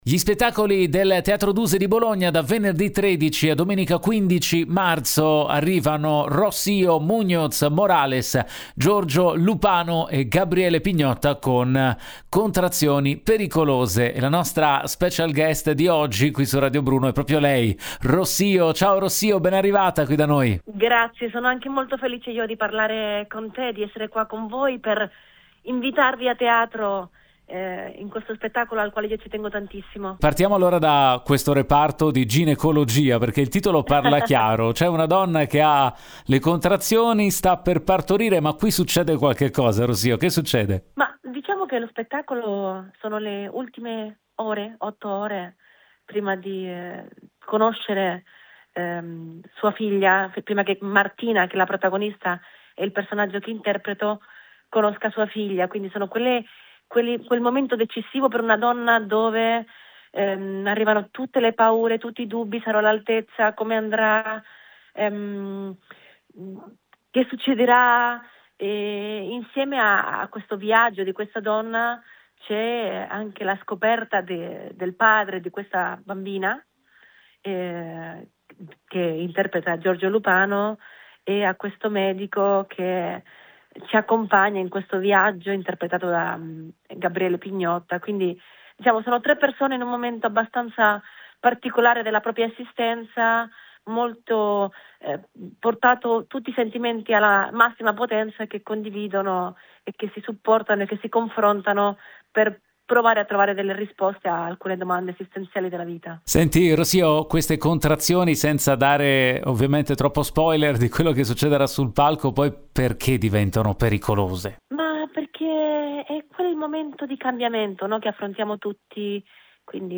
Home Magazine Interviste Rocío Muñoz Morales al Teatro Duse di Bologna con “Contrazioni pericolose”